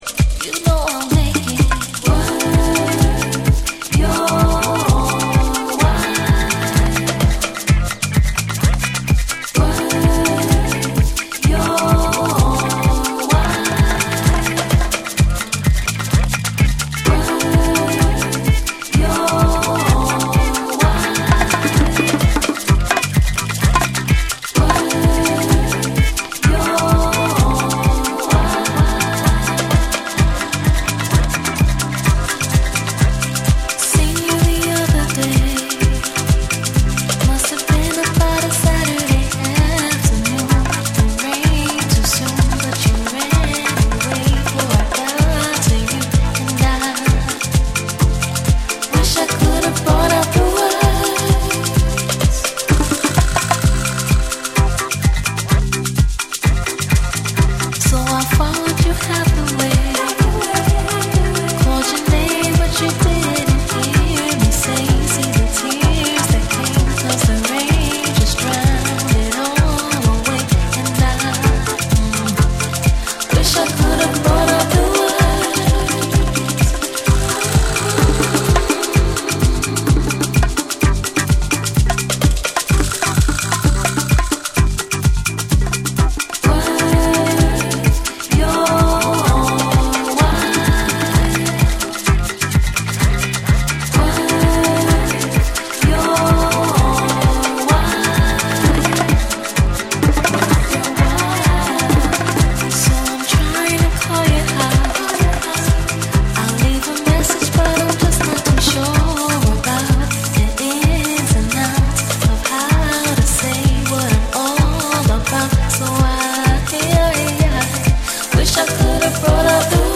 format : 12inch
TECHNO & HOUSE / ORGANIC GROOVE